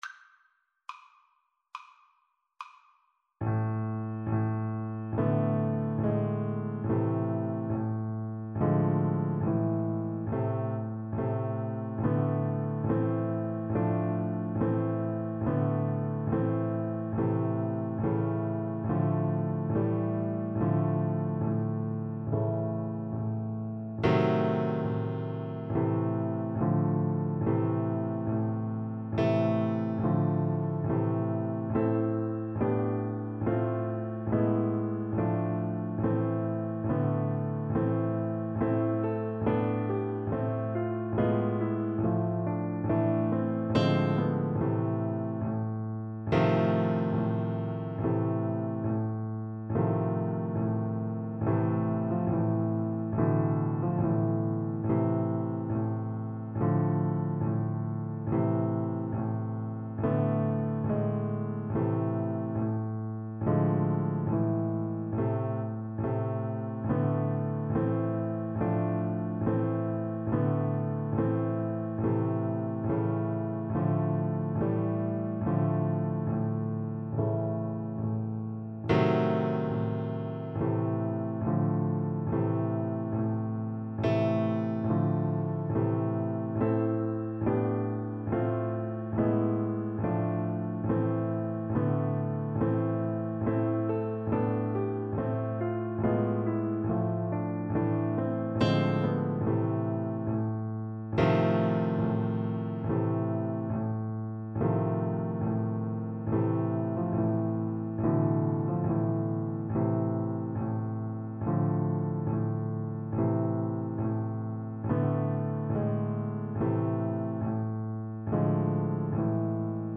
Cello version
4/4 (View more 4/4 Music)
Andante sostenuto =70
Classical (View more Classical Cello Music)
Russian